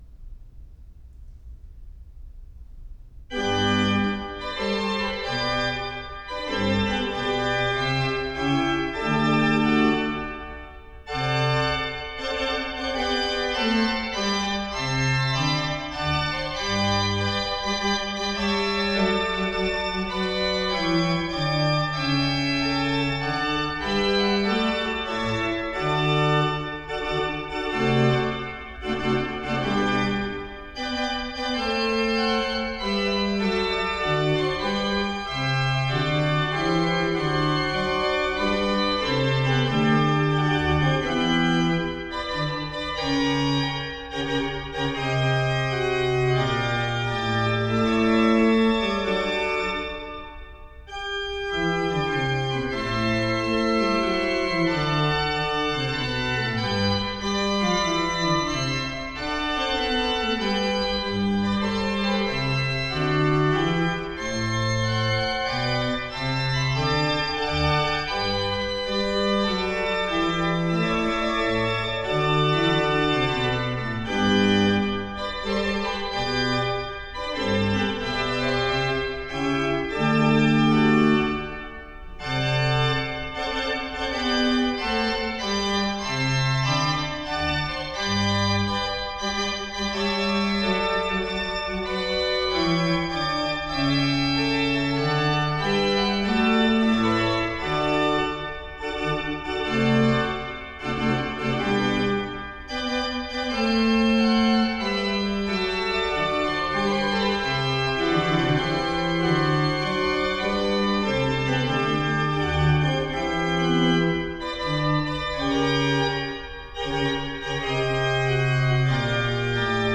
Worgan-Organ-Piece-No.8-in-G-major.mp3